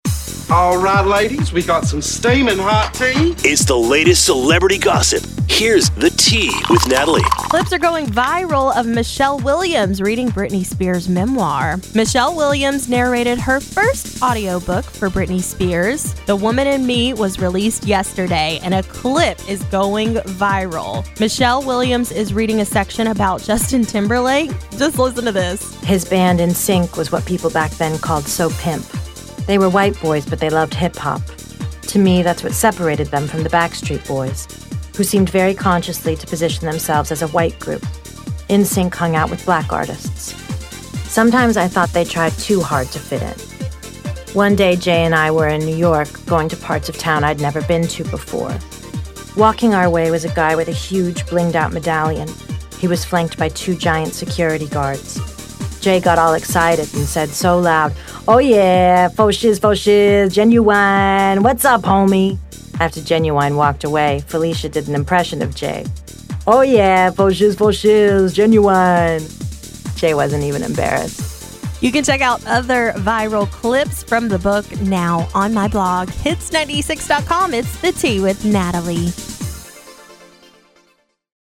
Clip Of Michelle Williams Reading Britney Spears’ Memoir Goes Viral
Michelle Williams narrated her first audiobook with Britney Spears’ memoir, The Woman in Me, which was released on Tuesday (October 24th). A clip of The Fabelmans actress reading a section about Justin Timberlake has since gone viral.
“Michelle Williams is doing performance art here, you see,” one person wrote on X. Another added, “(white) Michelle Williams doing an impression of Britney doing an impression of Justin Timberlake doing an impression of a Black person – this is art.”